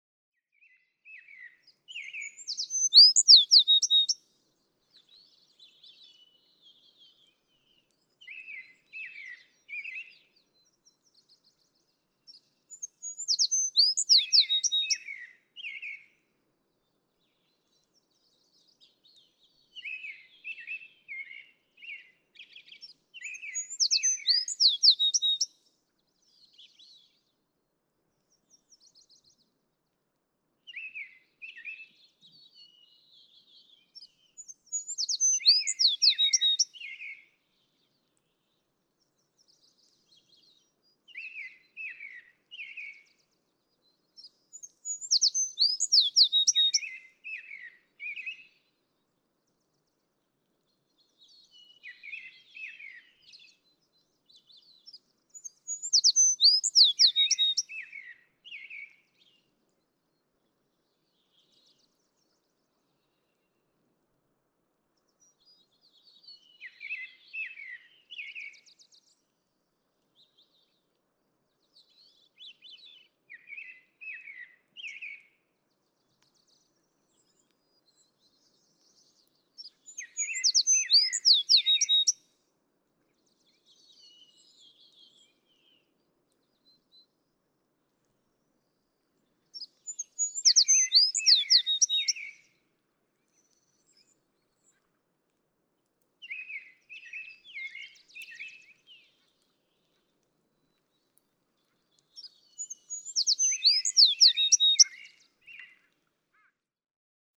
American tree sparrow
♫272, ♫273, ♫274—longer recordings from those three individuals
273_American_Tree_Sparrow.mp3